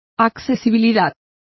Complete with pronunciation of the translation of accessibility.